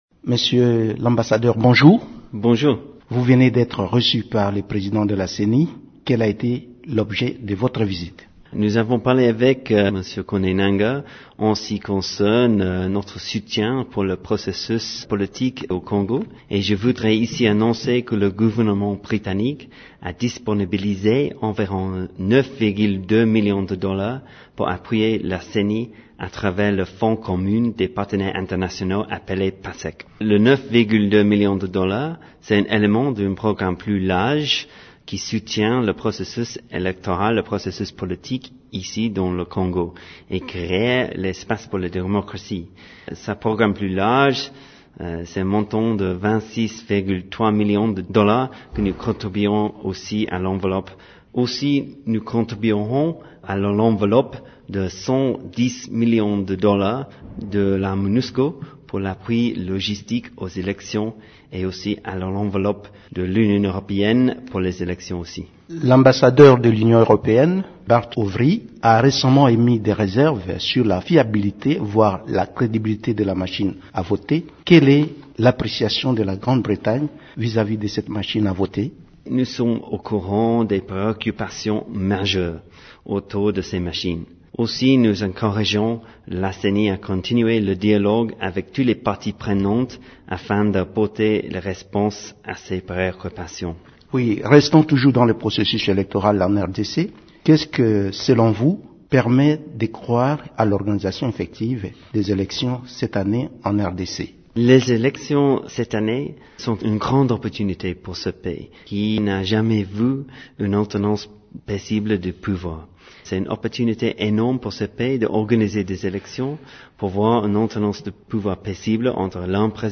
John Murton s'entretient